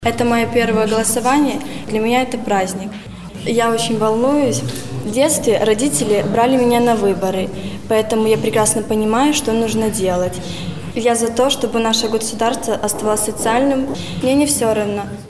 Девушка рассказала, с каким настроением подошла к ответственному событию.